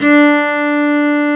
Index of /90_sSampleCDs/Roland - String Master Series/STR_Cb Bowed/STR_Cb3 Arco nv
STR CELLO 06.wav